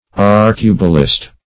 Arcubalist \Ar"cu*ba*list\